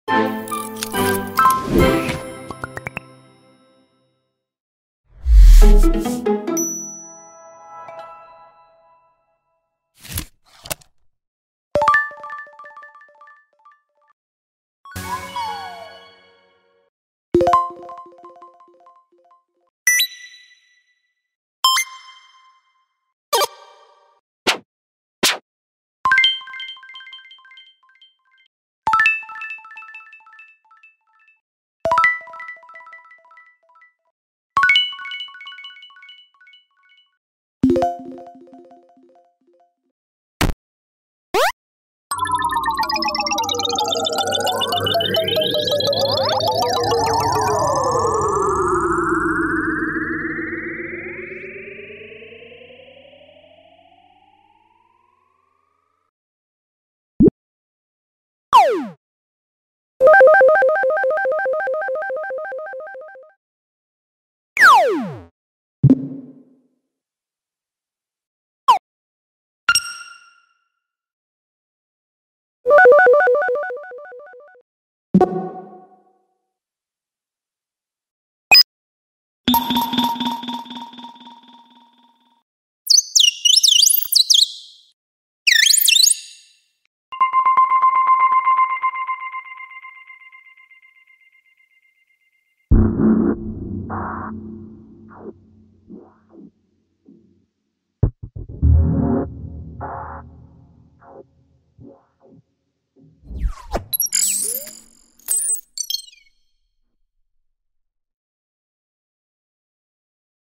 장르 효과음